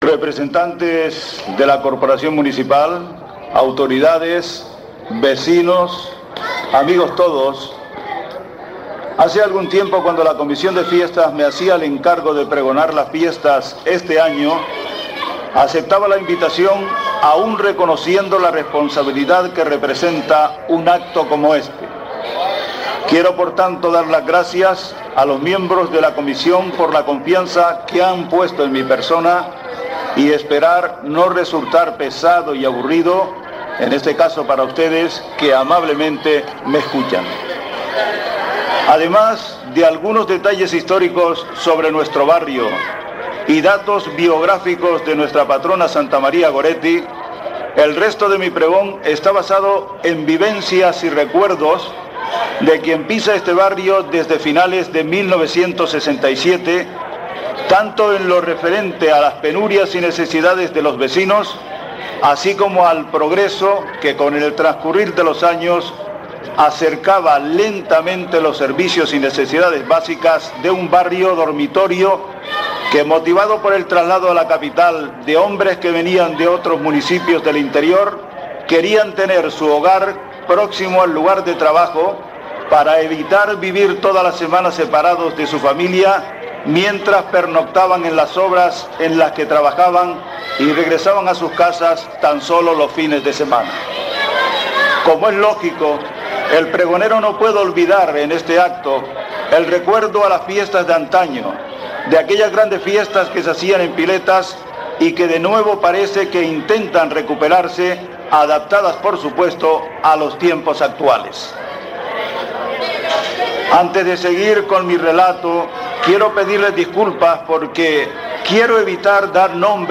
Pregón